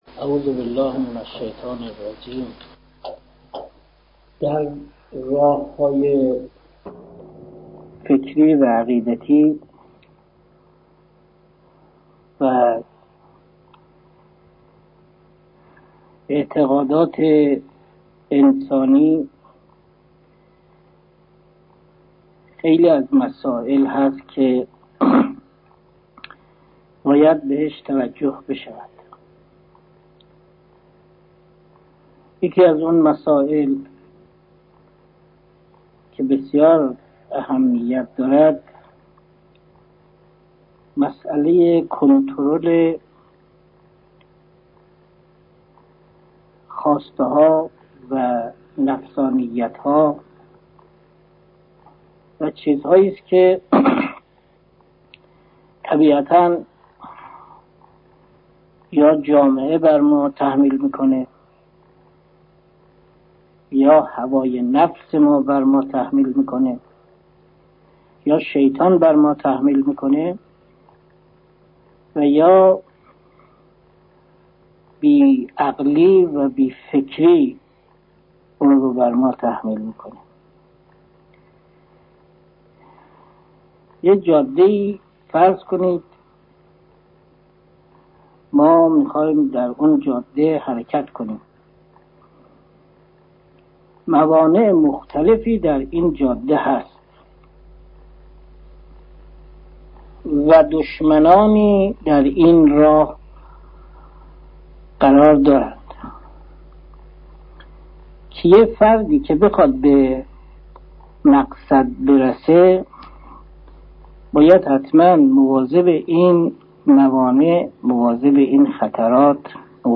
درس خصوصی غذای روح: جلسه سوم